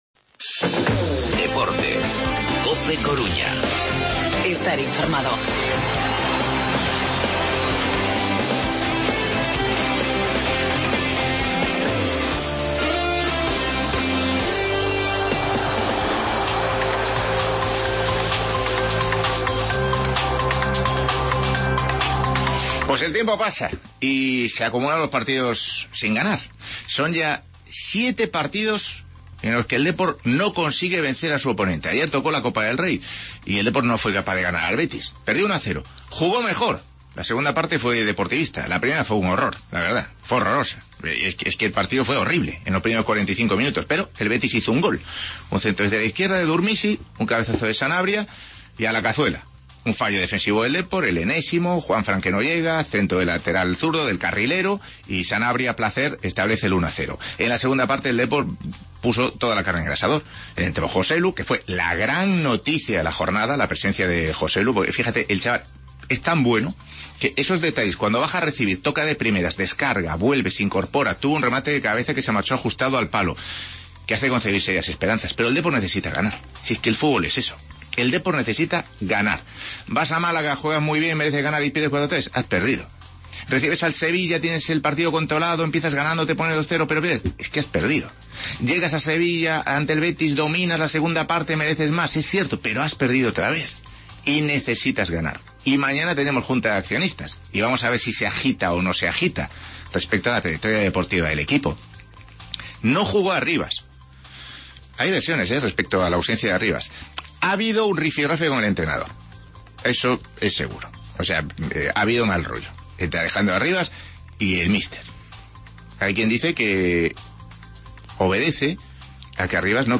Redacción digital Madrid - Publicado el 30 nov 2016, 16:04 - Actualizado 19 mar 2023, 02:57 1 min lectura Descargar Facebook Twitter Whatsapp Telegram Enviar por email Copiar enlace Escuchamos las valoraciones de Garitano, Álex Bergantiños y Joselu del Betis-Deportivo de la Copa del Rey. Y el análisis de Paco Liaño.